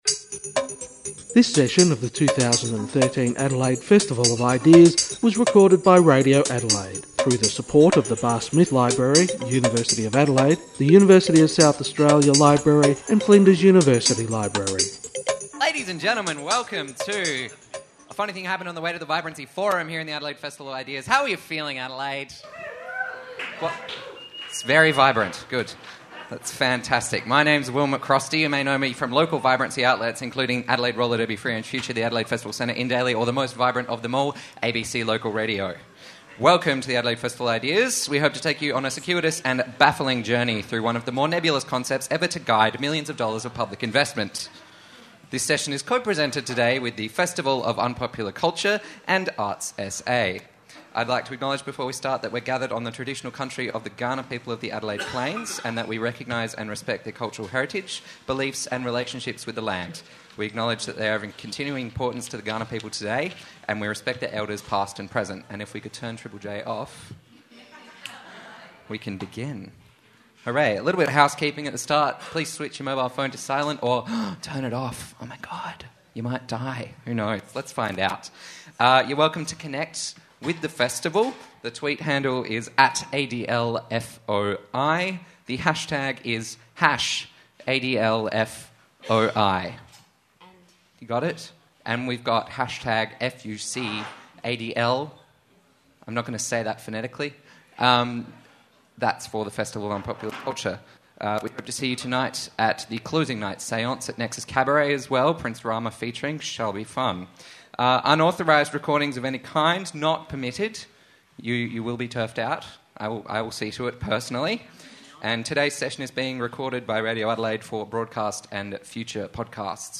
Too much of the discussion about creative industry involves either the demonisation of public servants or the dismissal of practitioners at the coalface. Five battle-weary veterans attempt to inject some meaning back into the dreaded V-word.